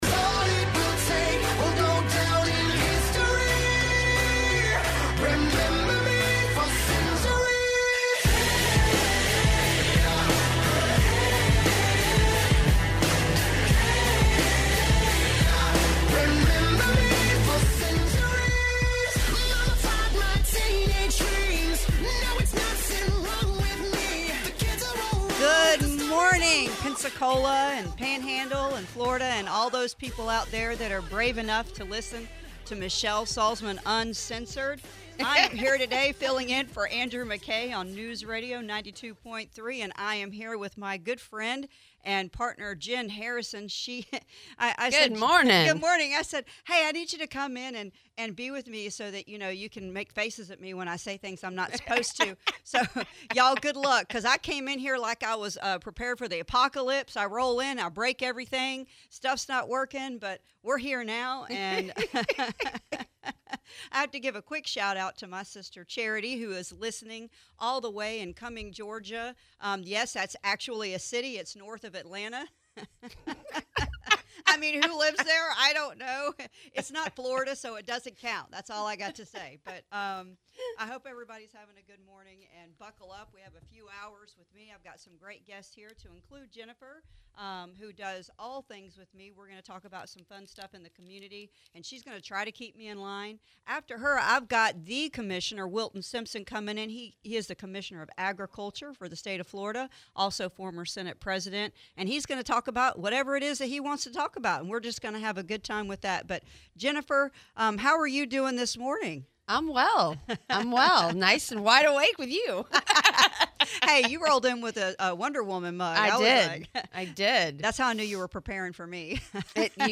Michelle Salzman is hosting the show today, she introduces herself and the term "What the Florida" to listeners and interviews FL Agricultural Commissioner Wilton Simpson